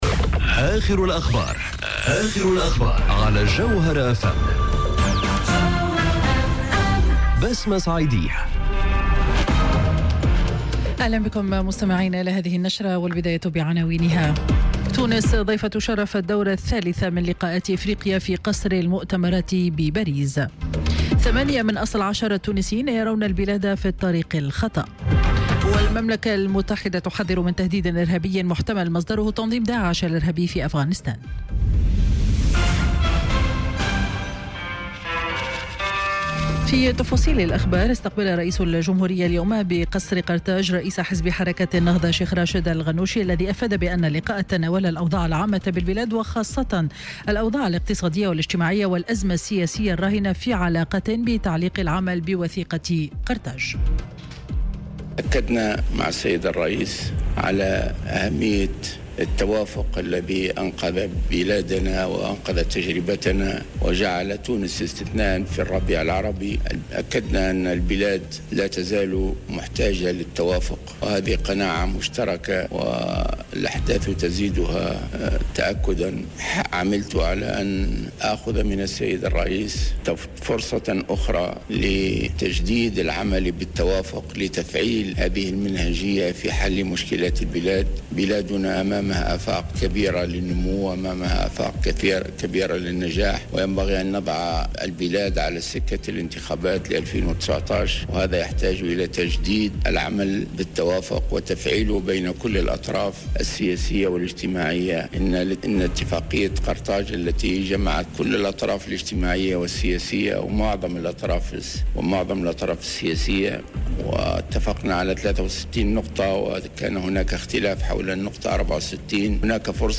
نشرة أخبار السابعة مساء ليوم الاثنين 3 سبتمبر 2018